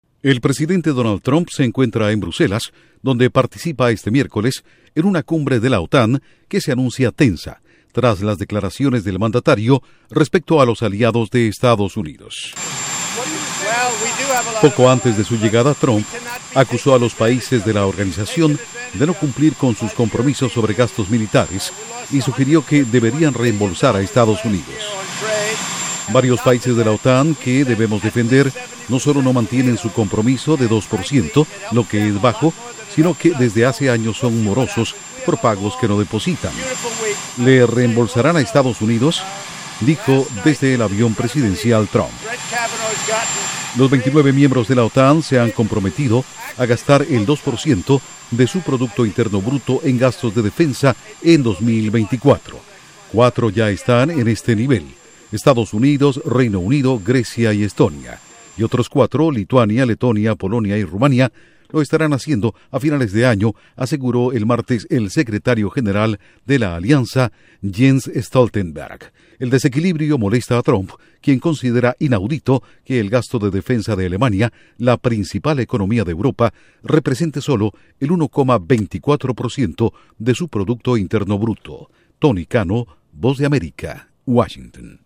Trump llega a Bruselas donde participa en la cumbre de la OTAN. Informa desde la Voz de América en Washington